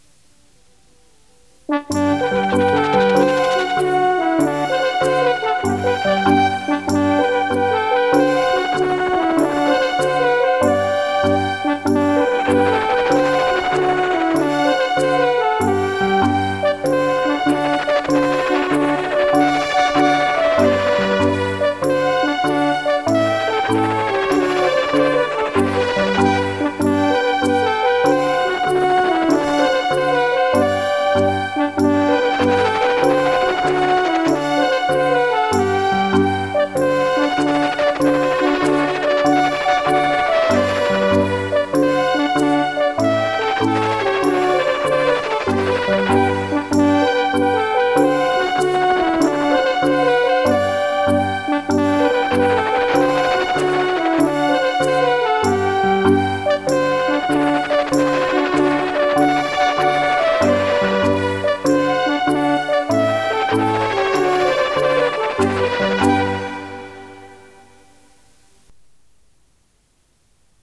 De groene bundel K1, de blauwe bundel K2, de derde bundel K3 heeft geen kleur gekregen maar heeft als titel “Zeg wil je met me dansen” en bevat 22 gezongen kinderdansen.